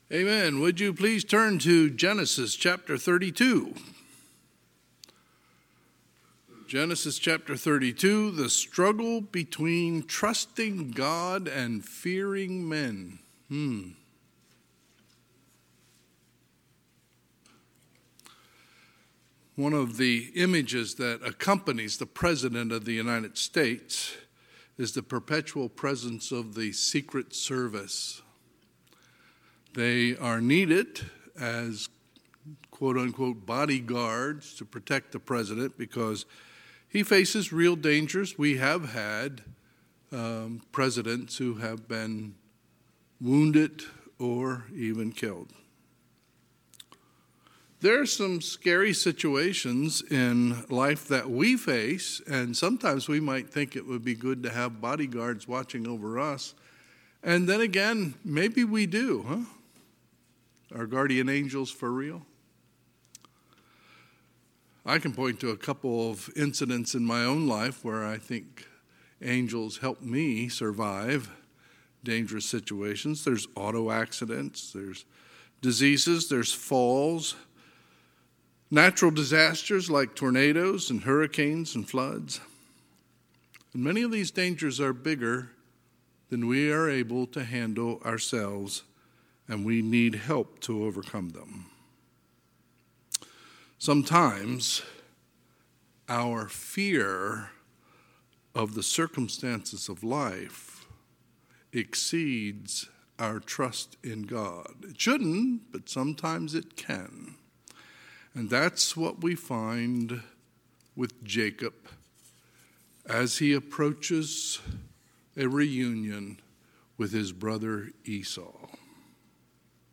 Sunday, November 20, 2022 – Sunday PM